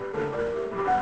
tfworld-hackathon / output / piano / 32-4.wav